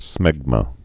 (smĕgmə)